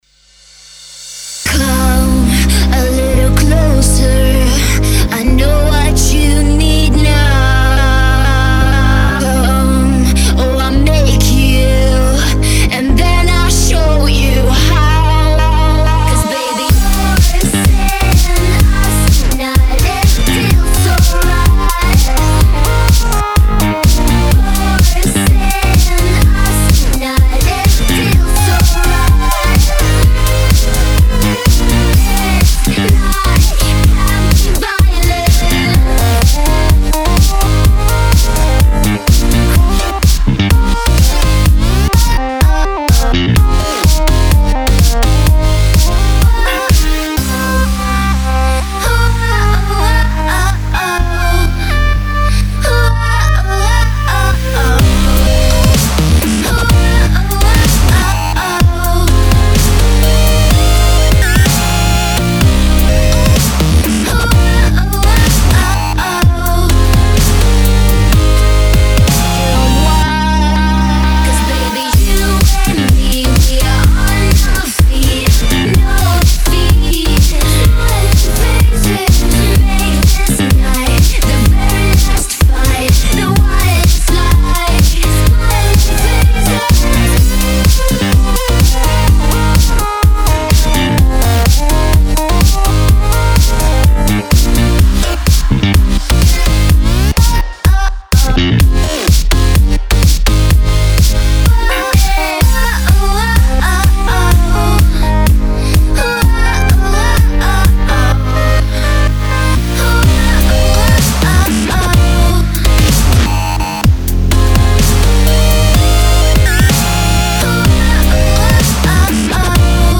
New bootleg
I'm a sucker for French House though.